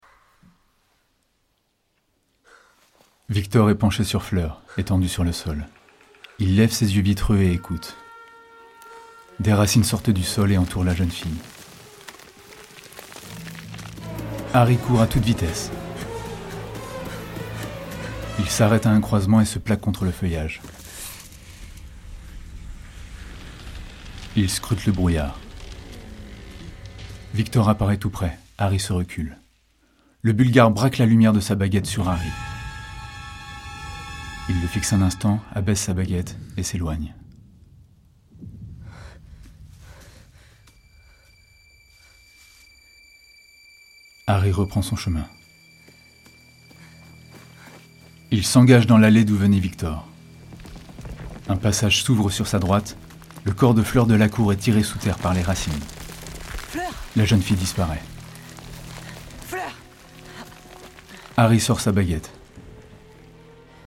Audio description Harry Potter.
- Baryton